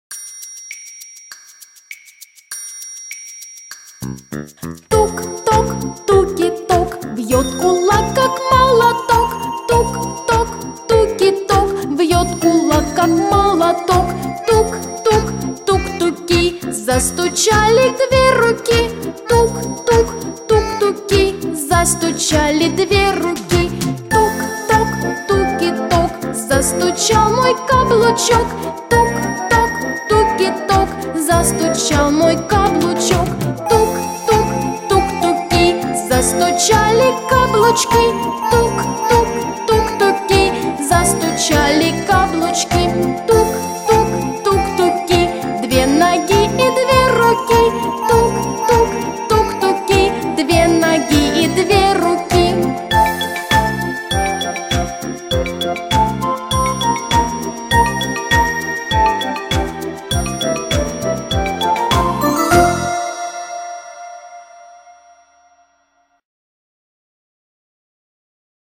Пальчиковые игры